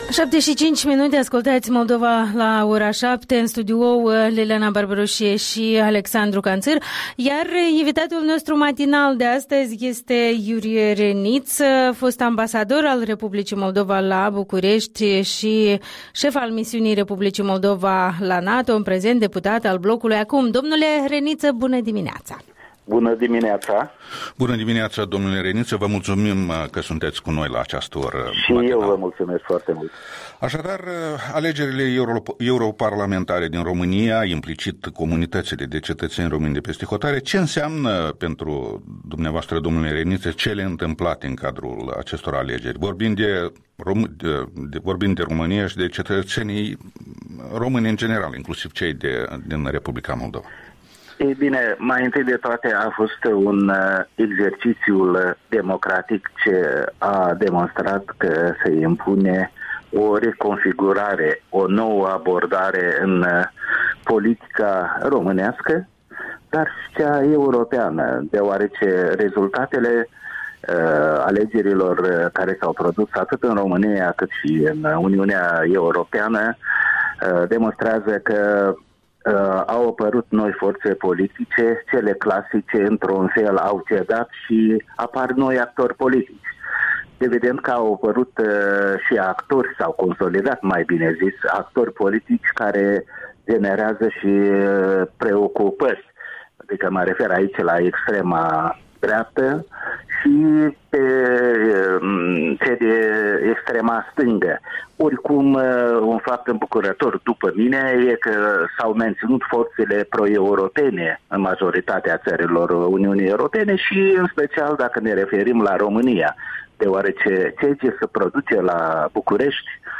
Interviul dimineții cu cu deputatul Blocului ACUM, fost ambasador al R. Moldova la București.
Interviul dimineții: cu Iurie Reniță